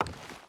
Footsteps / Wood / Wood Walk 4.wav
Wood Walk 4.wav